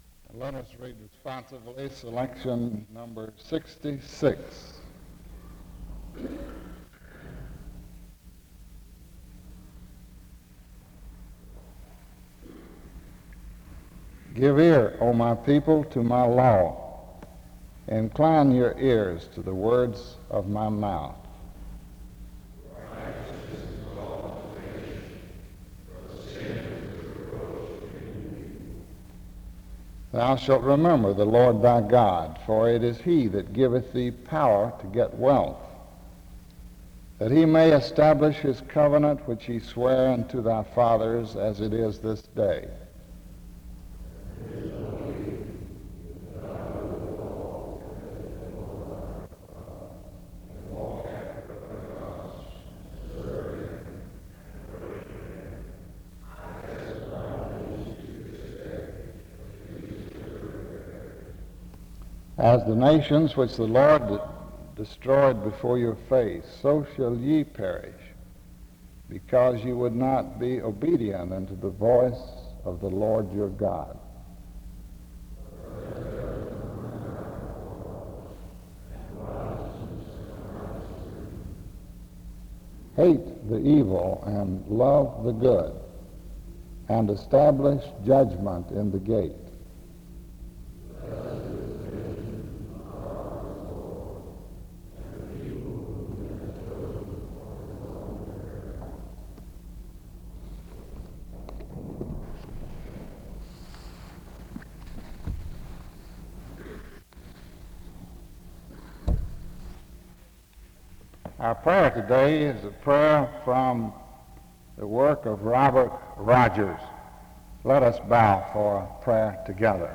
The service opens with a responsive reading and prayer from 0:00-3:37.